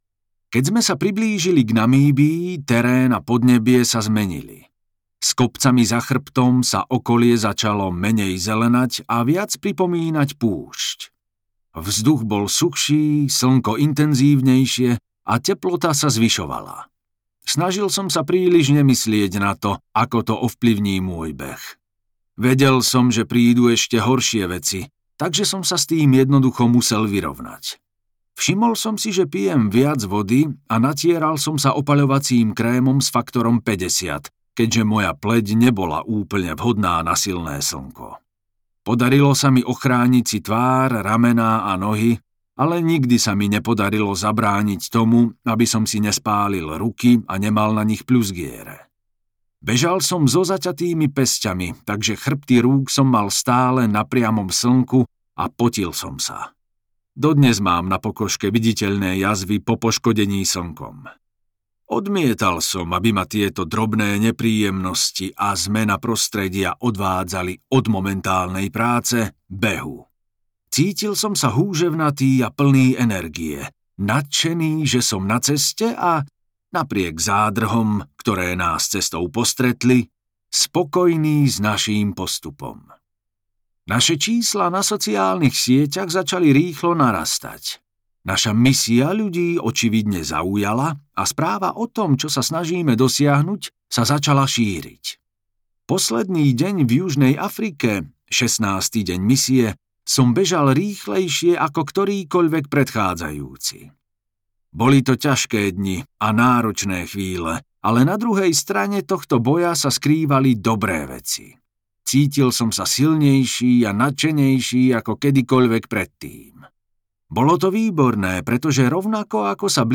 Najtvrdší týpek audiokniha
Ukázka z knihy